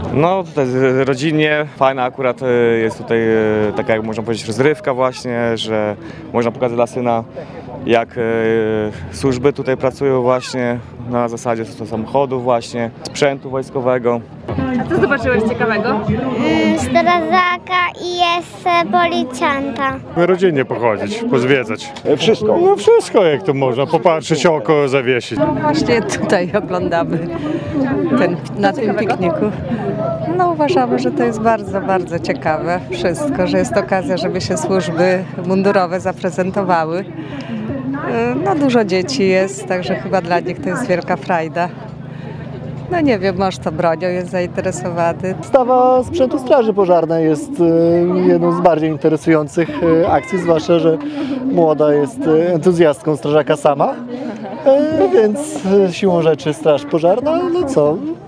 Nie zabrakło grochówki wojskowej, oraz punktów informacyjnych o służbach mundurowych – To bardzo ciekawa forma wypoczynku- mówili spotkani na majówce z mundurem przez nas suwalczanie.